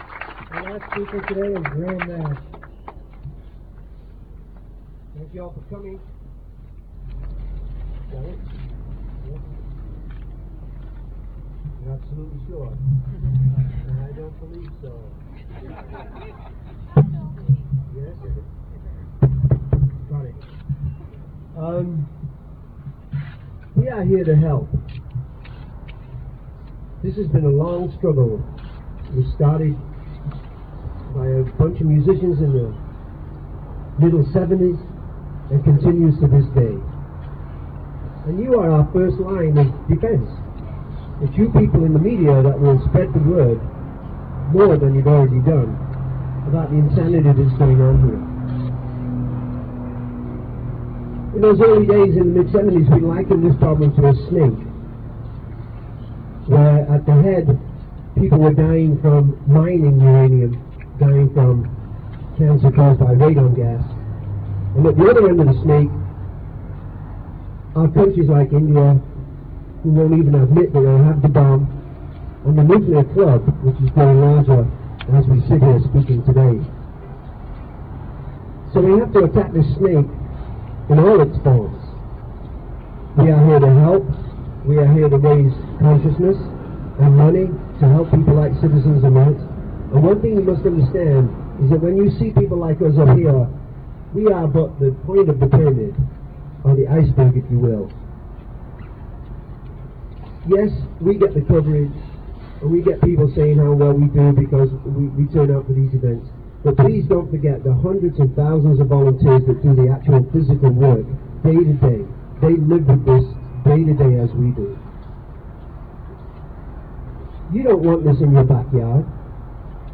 11. press conference - graham nash (2:24)